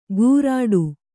♪ gūrāḍu